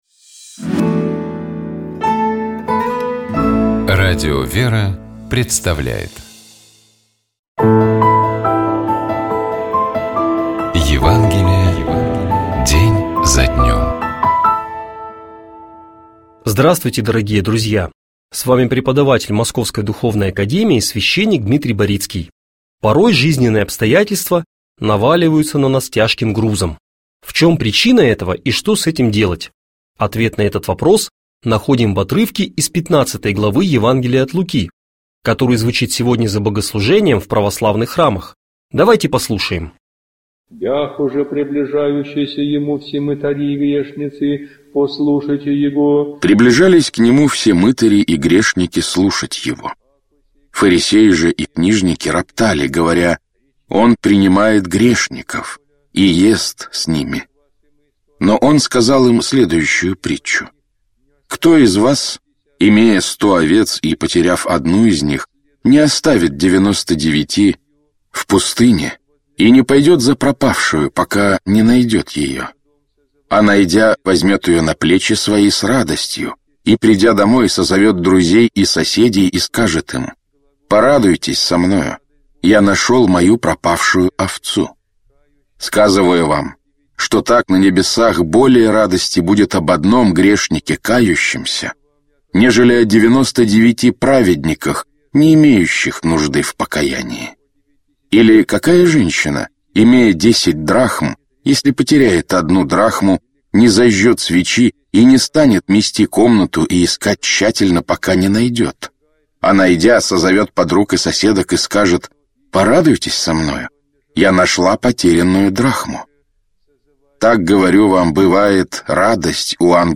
Читает и комментирует
епископ Феоктист ИгумновЧитает и комментирует епископ Переславский и Угличский Феоктист